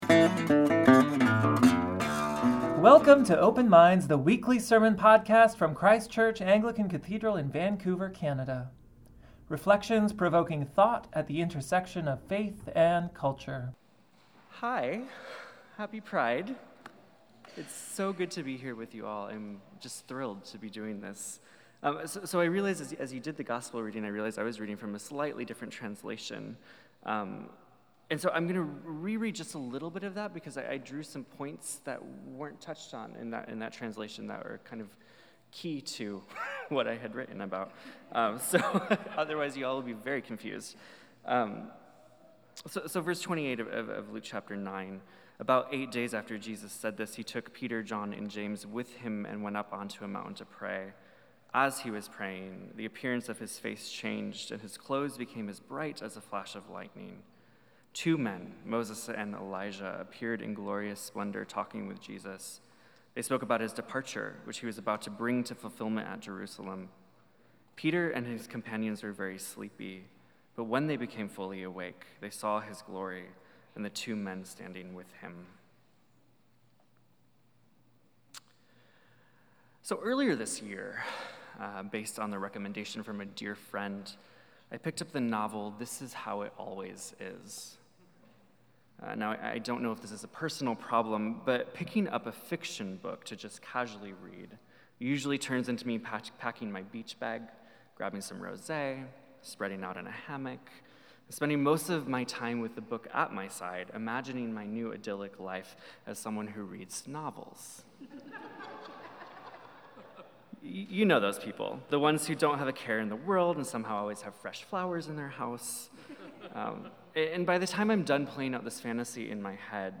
Sermons | Christ Church Cathedral Vancouver BC